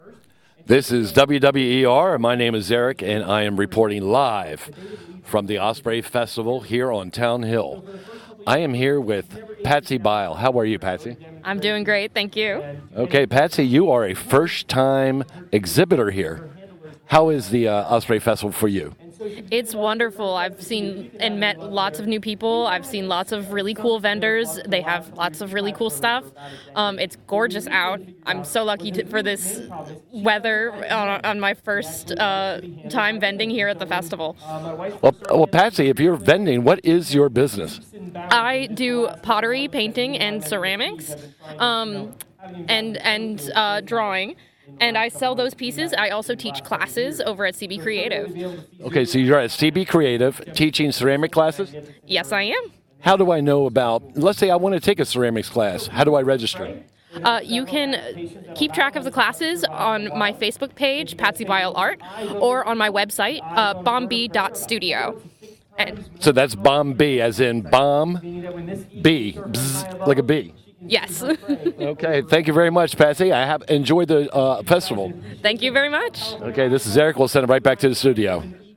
Osprey Festival